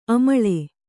♪ amaḷe